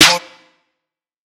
TS Snare_11.wav